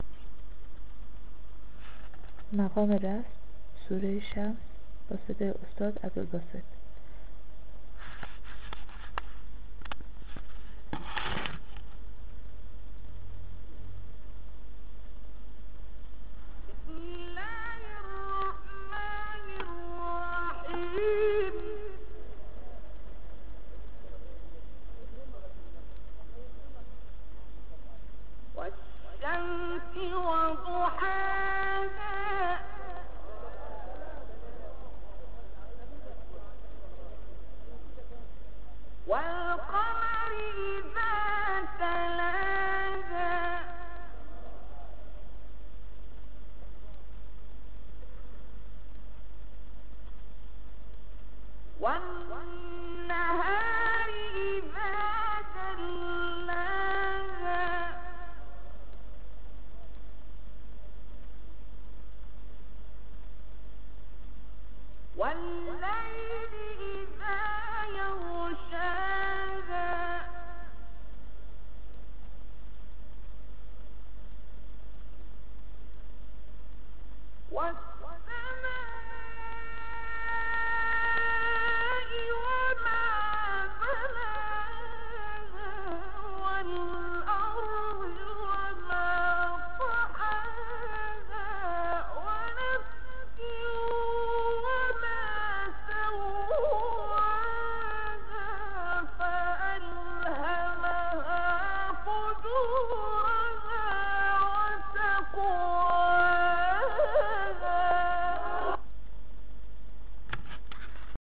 سایت قرآنی کلام نورانی - رست عبدالباسط سوره شمس (2).mp3